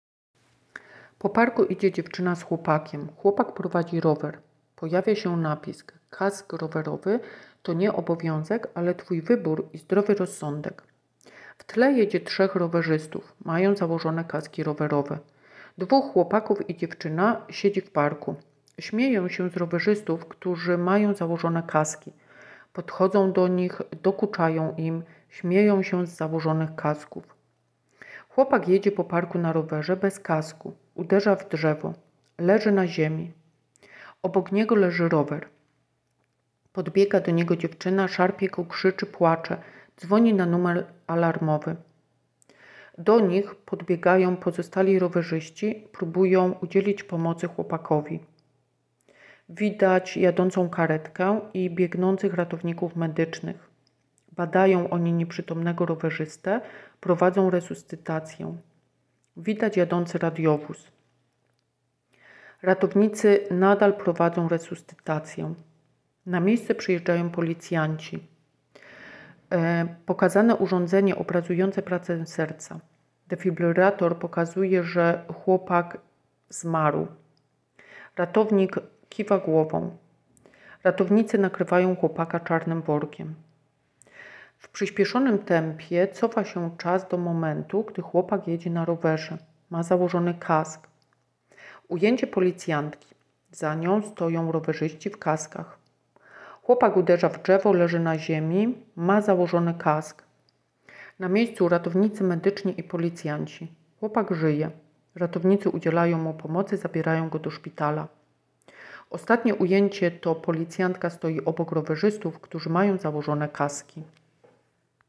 Nagranie audio Audiodeskrypcja spotu